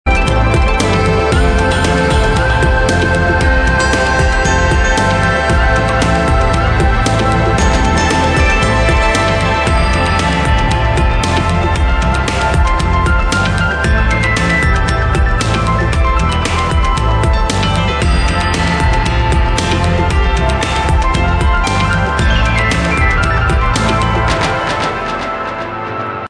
• Качество: 96, Stereo
без слов
красивая мелодия
Electronica
chillout
инструментальные
пианино
Ambient